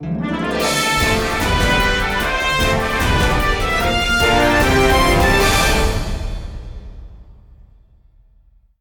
Higher quality rip from the Wii U version.